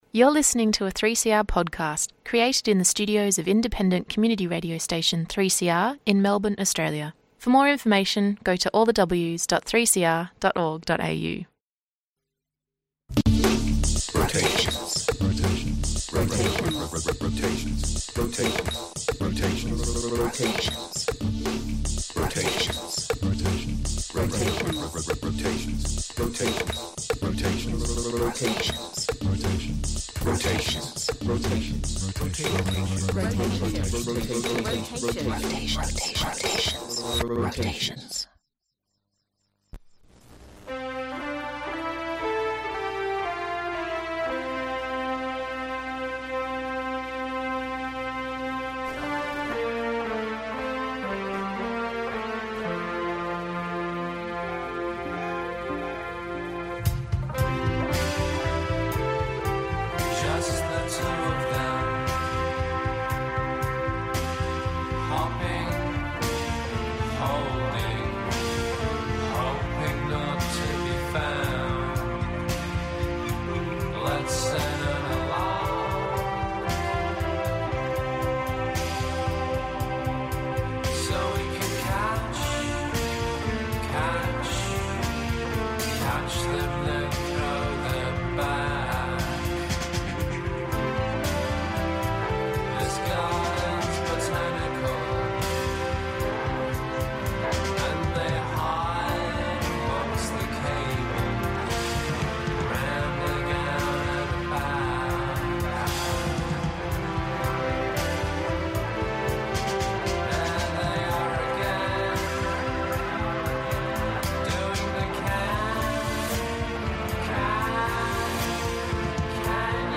Each week guest presenters from shows across the 3CR grid bring you their distinctive appreciation of music.